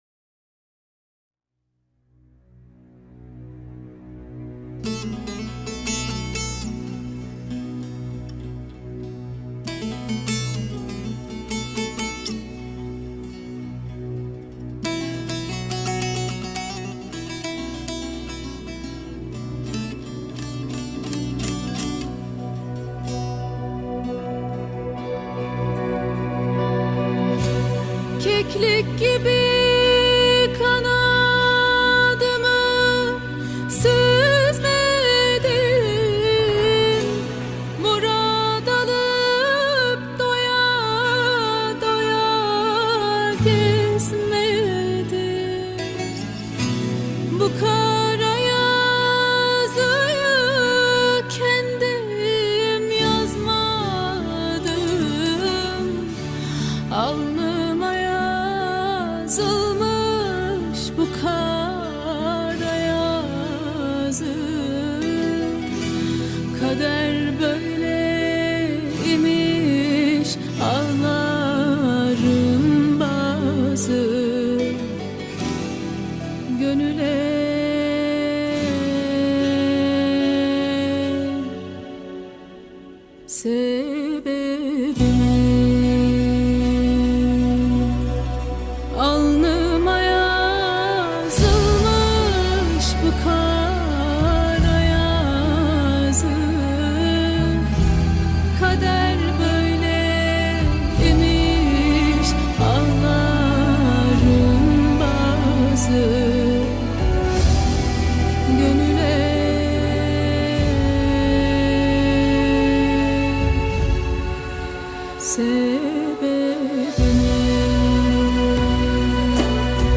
dizi müziği, duygusal hüzünlü üzgün şarkı.